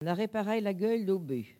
Collectif atelier de patois
Catégorie Locution